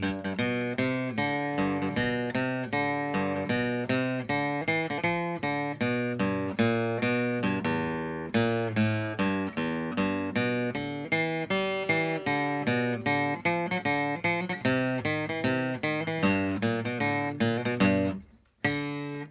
In our first set of results, we used some trumpet and guitar lesson recordings as the example data.
Guitar Lesson
guitar34.wav